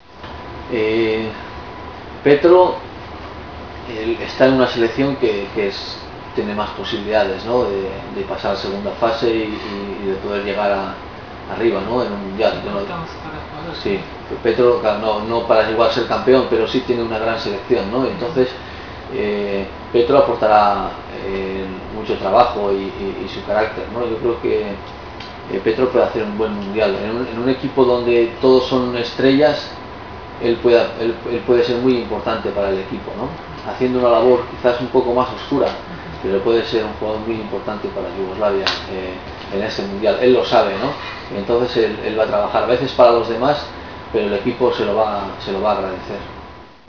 ワールドカップ出場経験者であるベギリスタイン選手にワールドカップについて、そして浦和レッズから代表として出場する３人の選手についてのコメントを語ってもらいました。